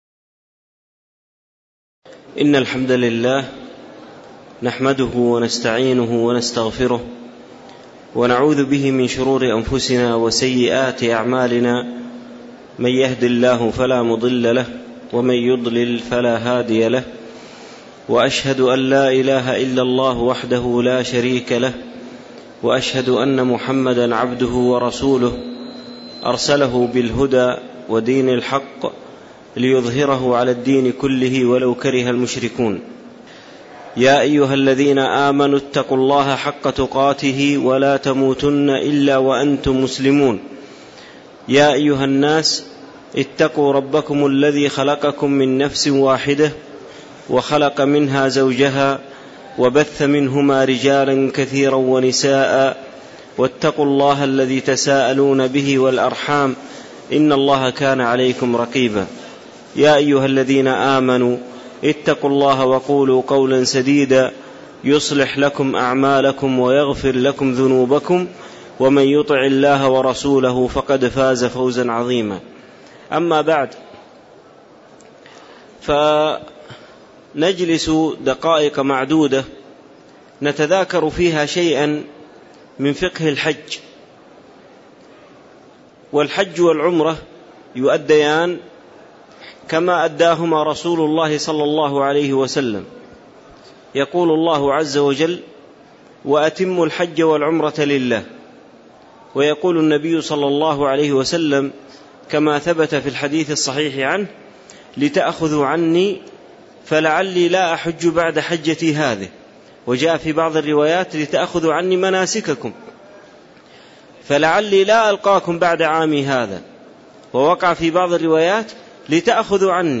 تاريخ النشر ١٧ ذو القعدة ١٤٣٧ هـ المكان: المسجد النبوي الشيخ